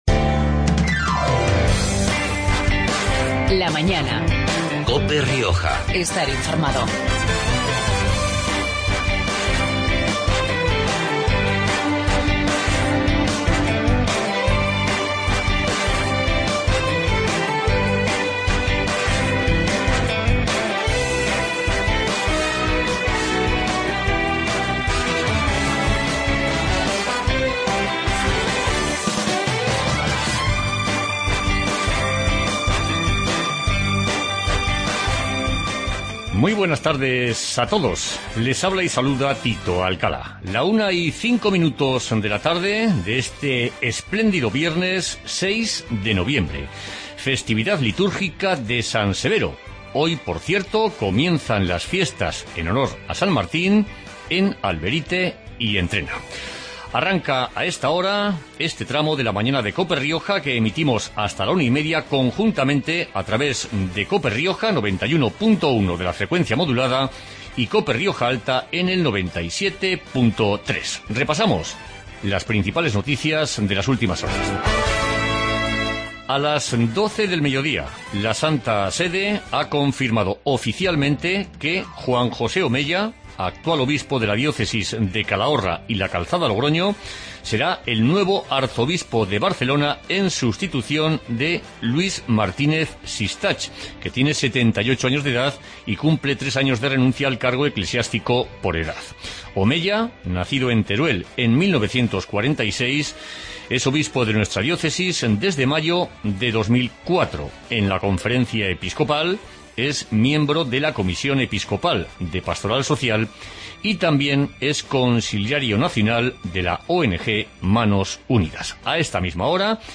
Magazine de actualidad de La Rioja